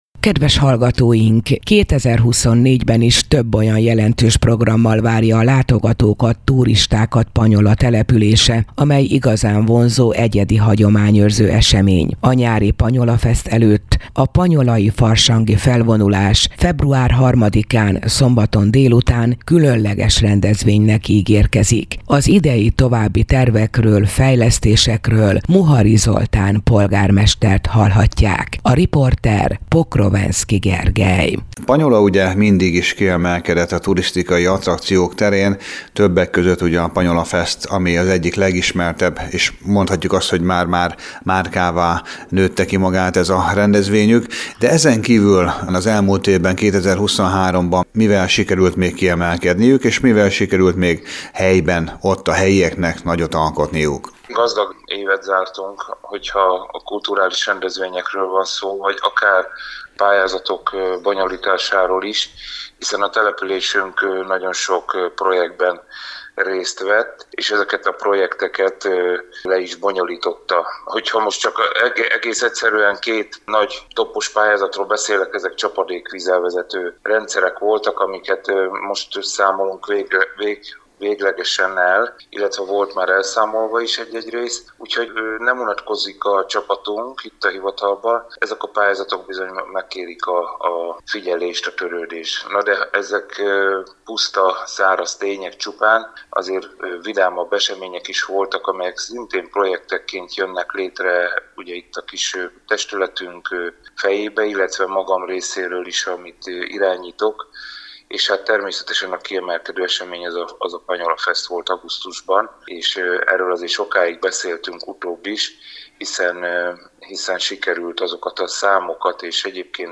A nyári PanyolaFeszt előtt a Panyolai Farsangi Felvonulás február 3-án szombat délután is különleges rendezvénynek ígérkezik. Az idei évi további tervekről, fejlesztésekről Muhari Zoltán polgármestert hallhatják.